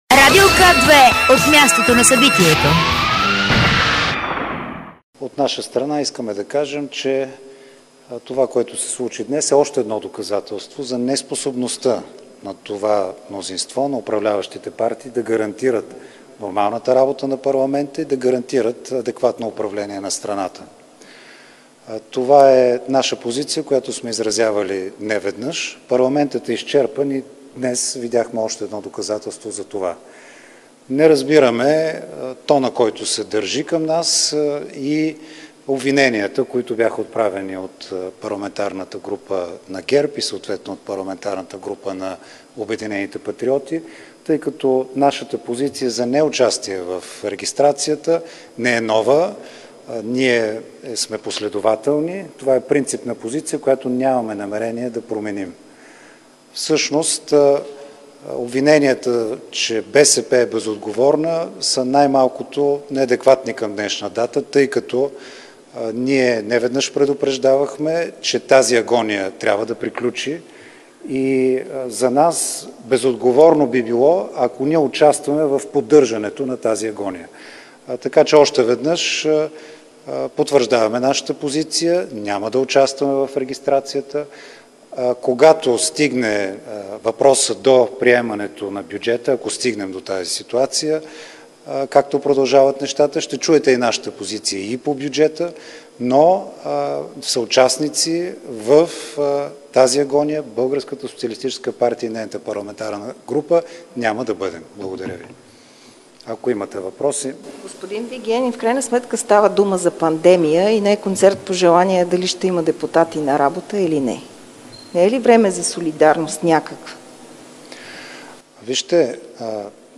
10.15 - Брифинг на независимите депутати за подбор на прокурорите директно от мястото на събитието (Народното събрание)
Директно от мястото на събитието